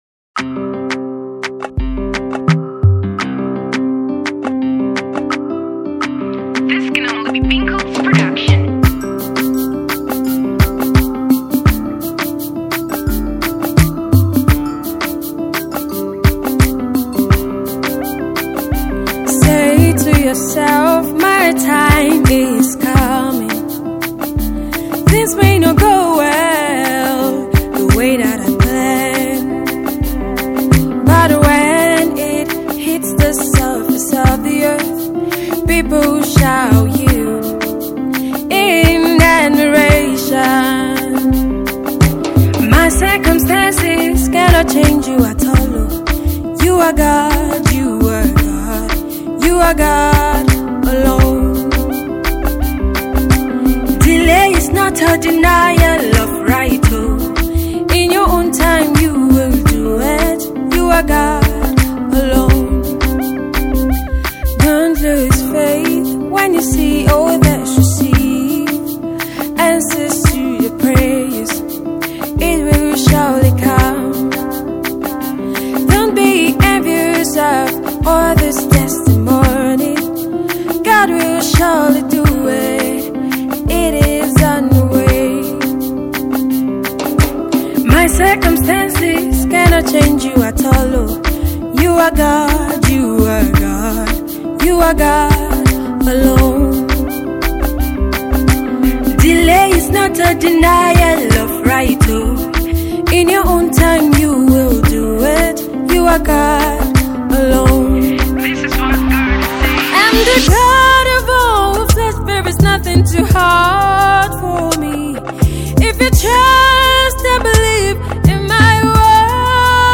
afro-pop gospel song